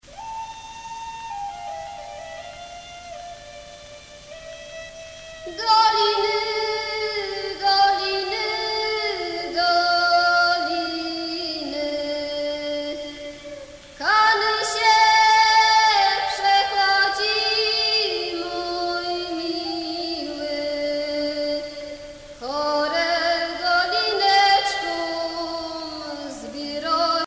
Enquête Lacito-CNRS
Pièce musicale inédite